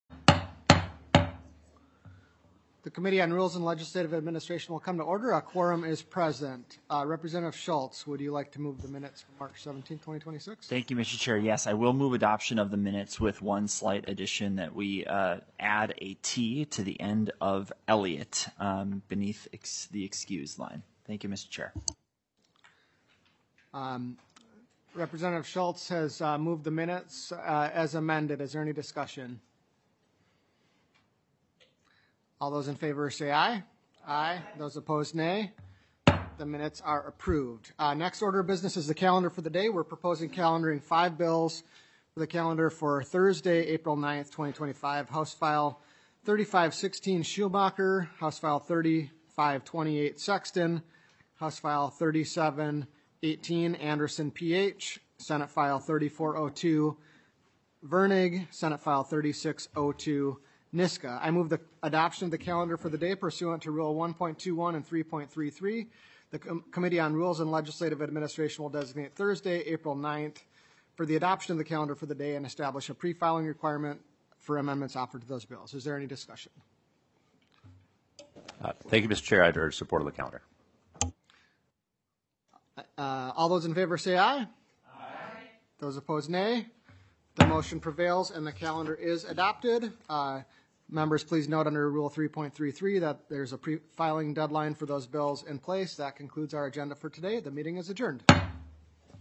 Representative Niska, Co-Chair of the Rules and Legislative Administration Committee, called the meeting to order at 10:00 AM, on April 7th, 2026, in Room G3 of the State Capitol Building.